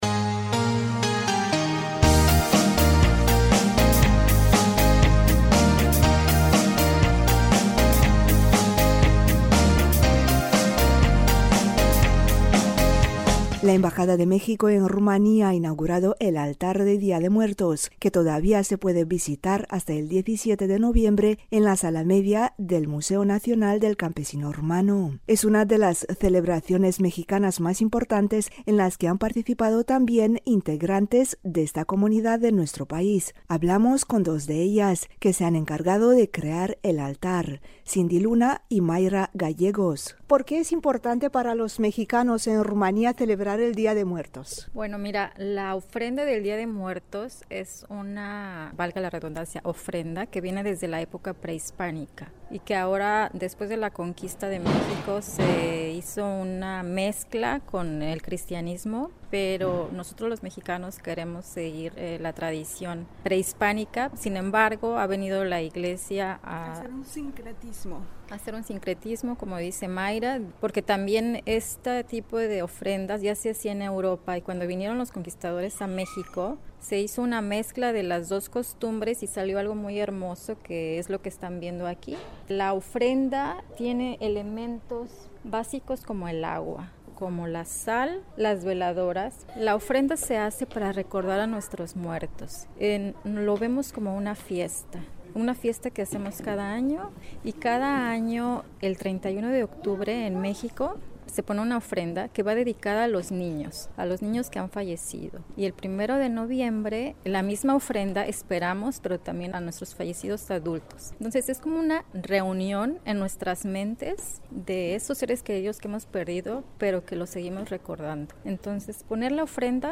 Hablamos con dos de ellas.